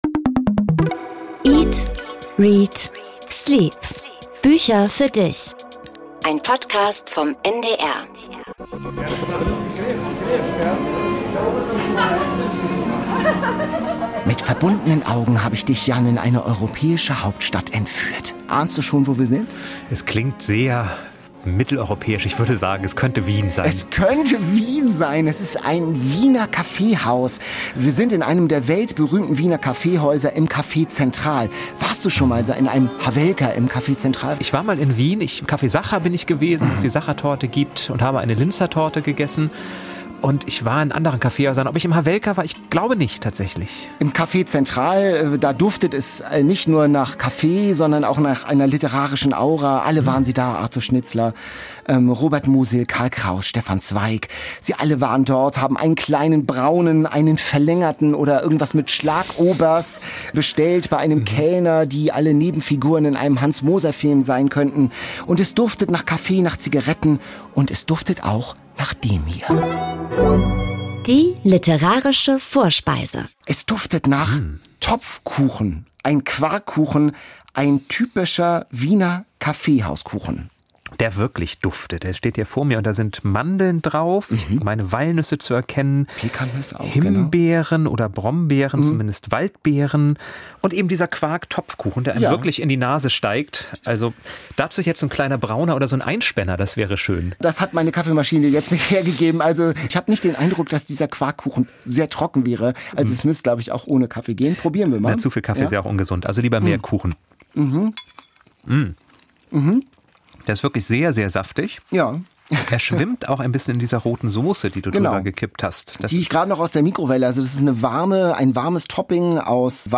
Kaffeehausgefühl im Podcaststudio